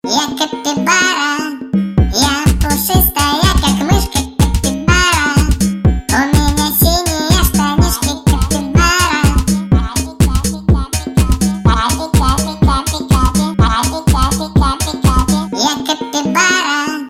веселые
electronic